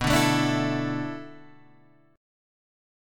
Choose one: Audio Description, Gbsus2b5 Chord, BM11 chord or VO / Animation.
BM11 chord